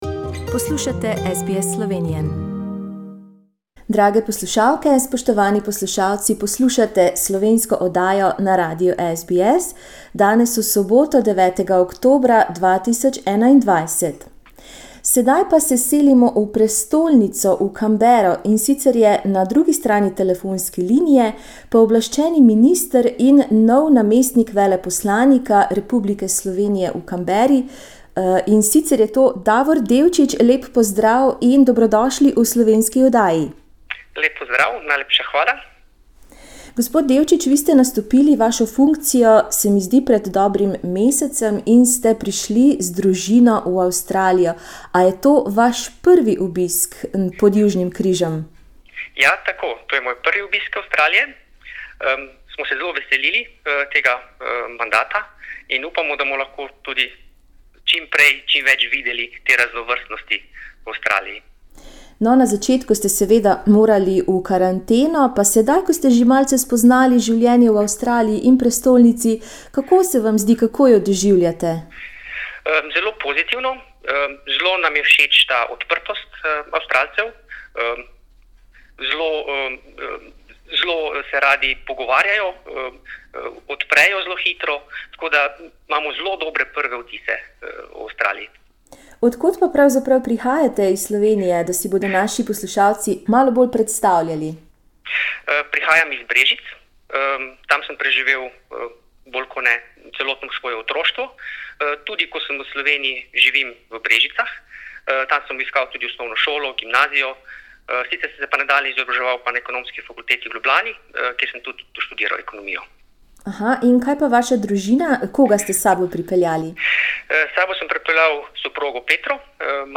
9.oktobra 2021 smo se pogovarjali z novim pooblaščenim ministrom in namestnikom veleposlanika RS v Avstraliji Davorjem Devčičem. Selitev v tujo državo ni nikoli lahka, kaj šele vsakih nekaj let, in to z družino! Prisluhnite nadvse zanimivemu klepetu.